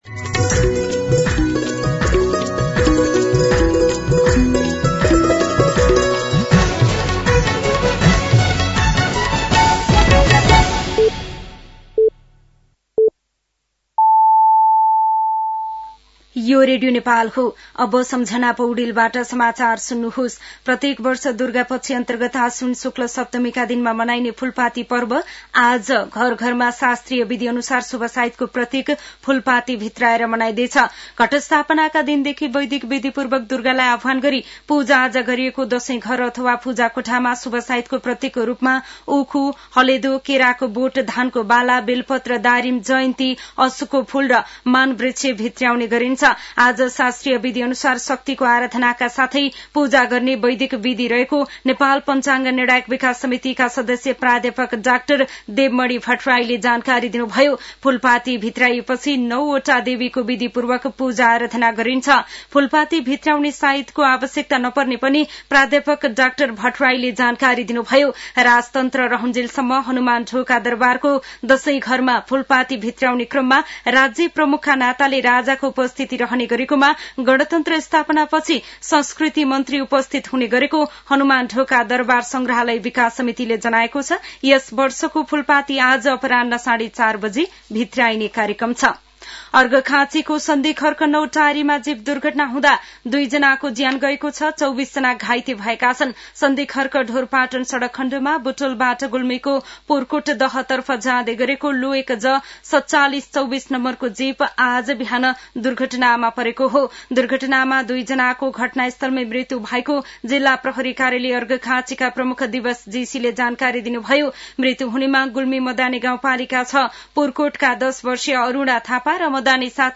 मध्यान्ह १२ बजेको नेपाली समाचार : १३ असोज , २०८२
12-pm-Nepali-News-5.mp3